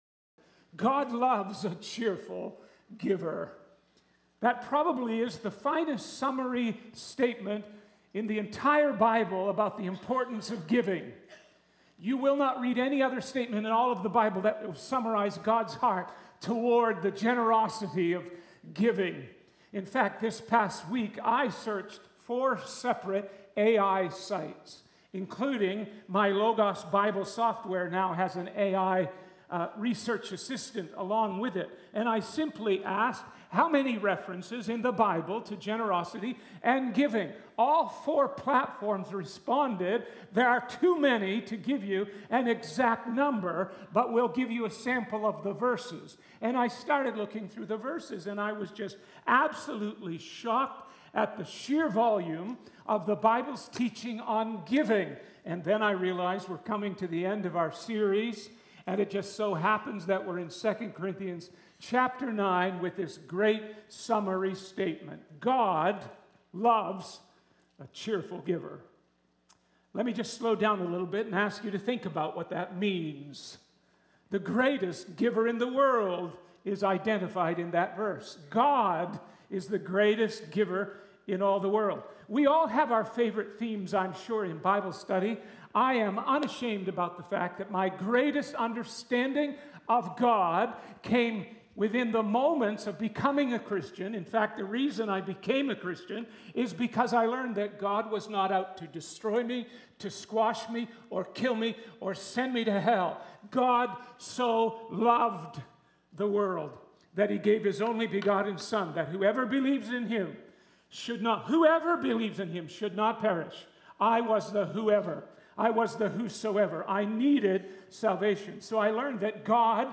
Sermon Notes: 1.